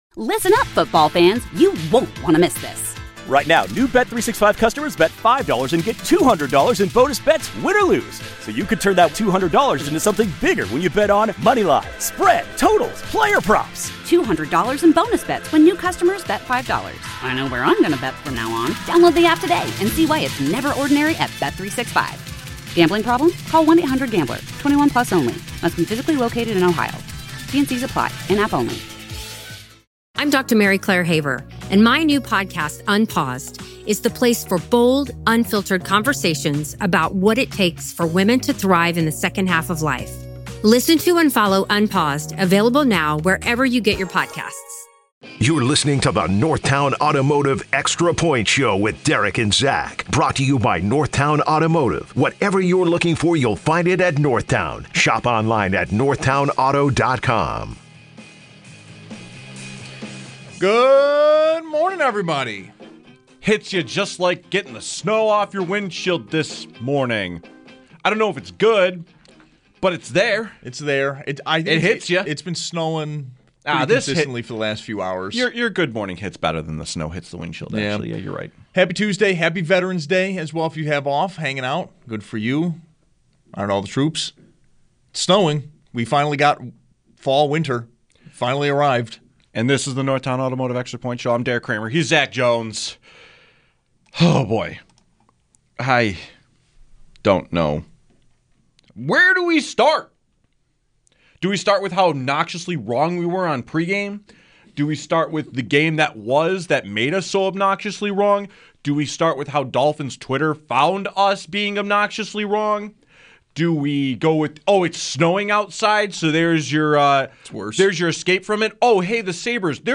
THE EXTRA POINT is designed for full engagement with bills fans as the two hosts open the phones, texts sand tweets to connect with the Bills mafia on everything Bills!